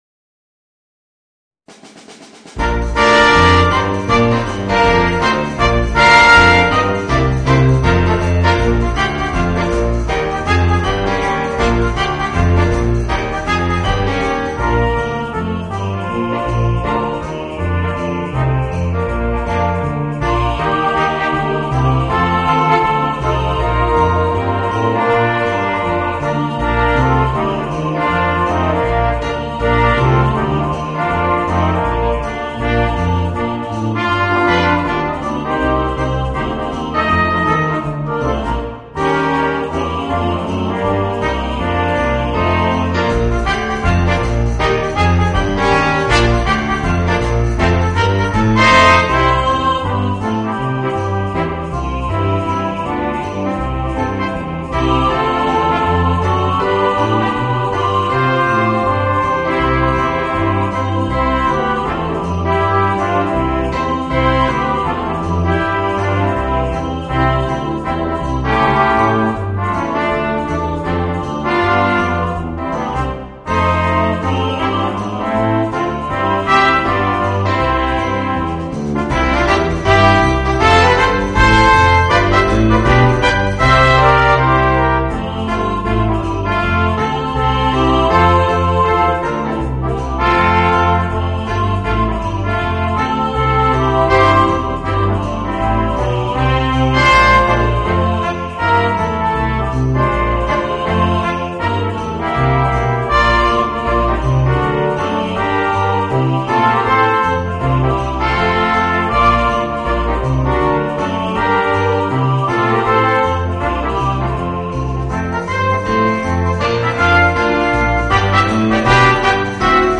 Voicing: 4 - Part Ensemble and Chorus